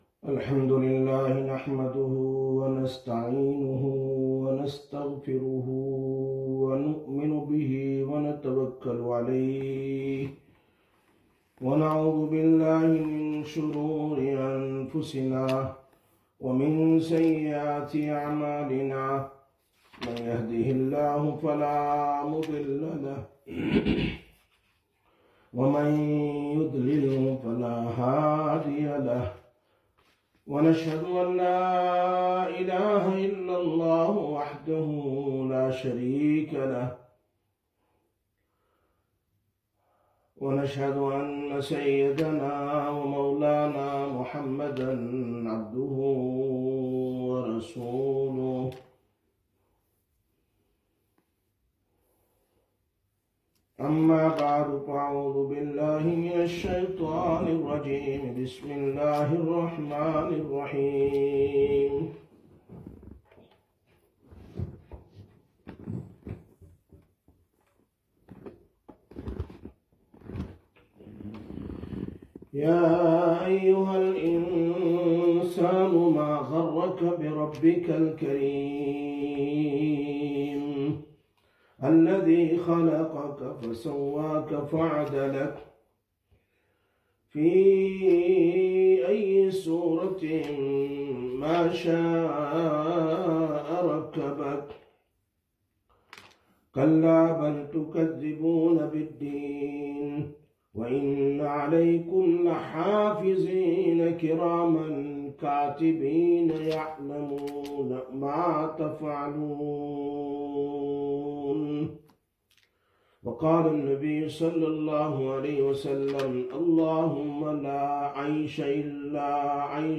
10/12/2025 Sisters Bayan, Masjid Quba